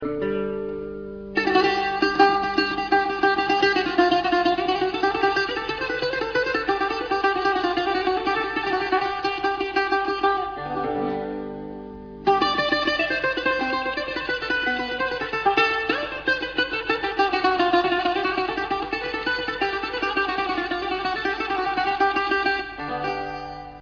Bouzouki solo
Valve amplified bouzouki